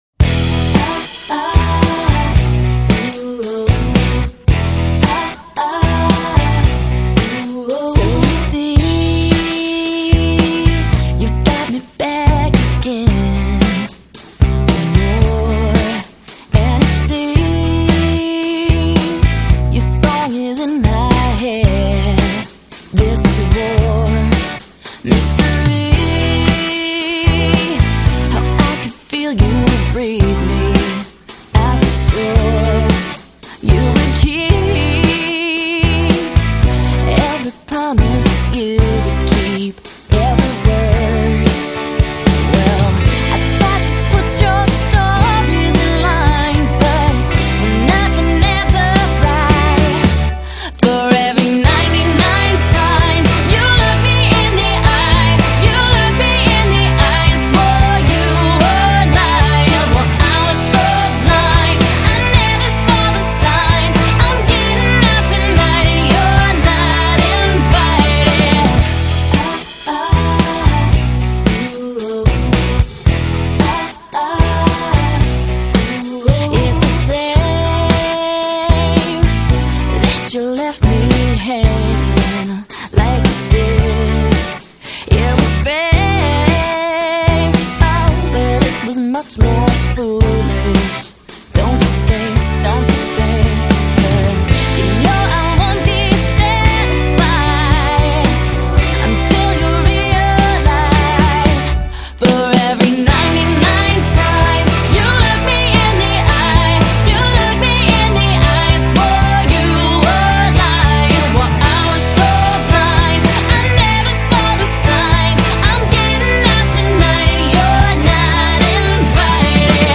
2009 Genre: Pop rock, Alternative rock Length